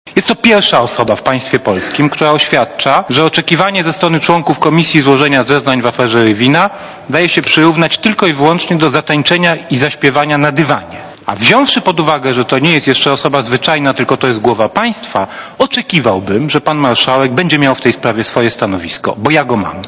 mówi Jan Rokita